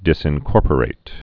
(dĭsĭn-kôrpə-rāt)